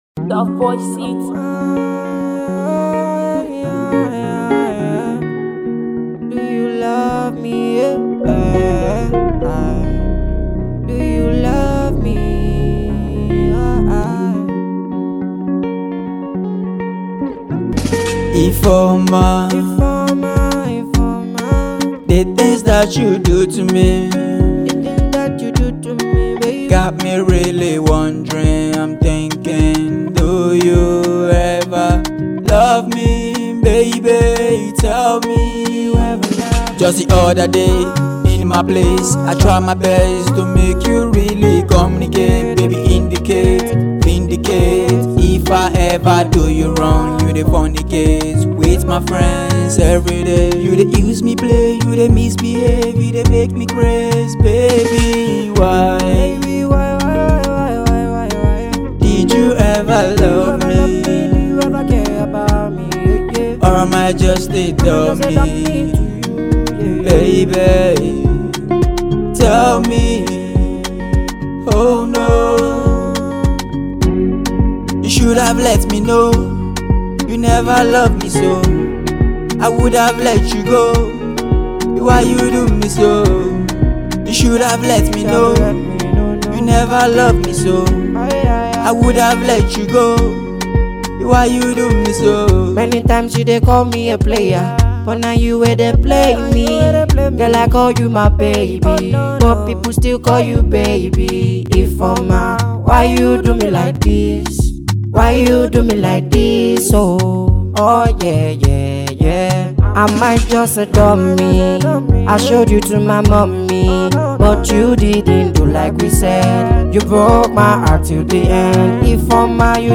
A romantic Afro-R&B ballad
with lush harmonies and a laid-back instrumental.